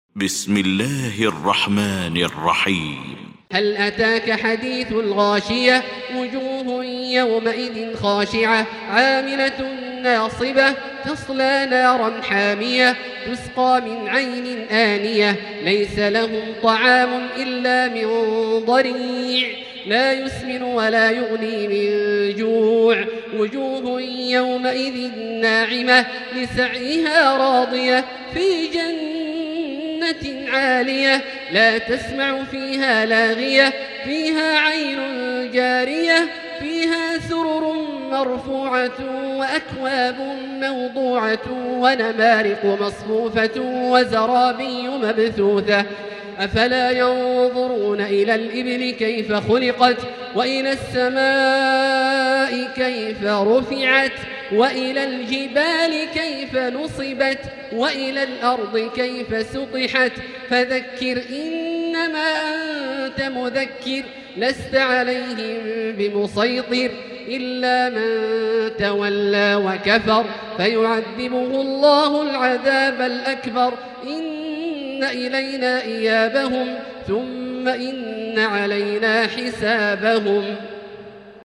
المكان: المسجد الحرام الشيخ: فضيلة الشيخ عبدالله الجهني فضيلة الشيخ عبدالله الجهني الغاشية